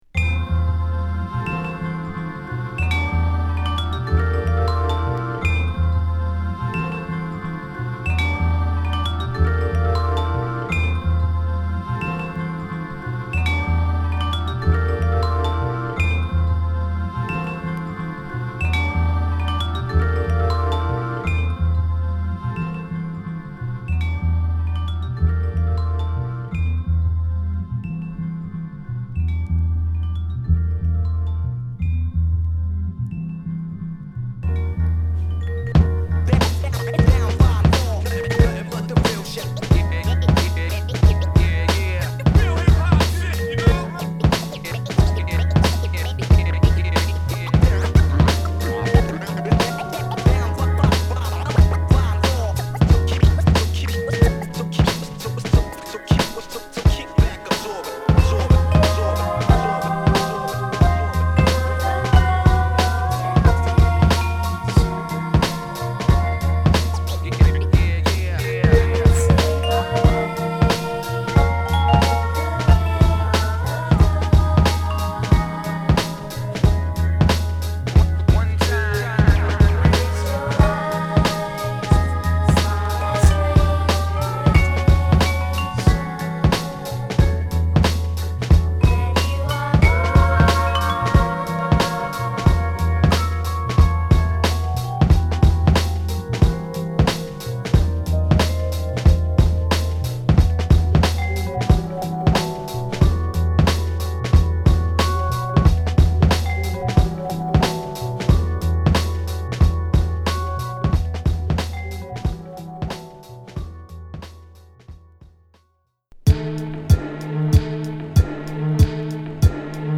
クオリティ高いインストHip Hop／ダウンテンポトラックを収録！